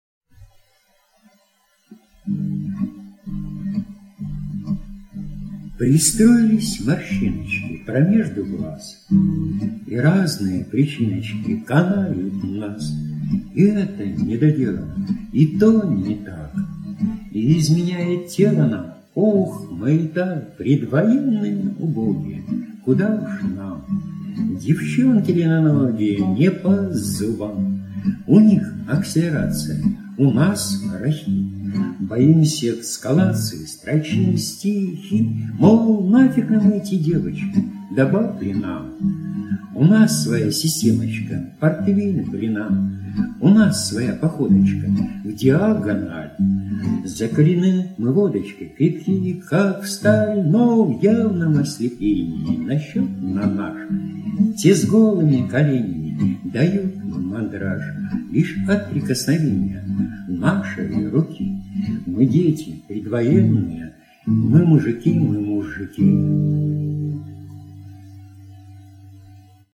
Ага! Доходит, как до жирафа :)...Уже скачала, прослушала...А Вы ничего так мурлыкаете :)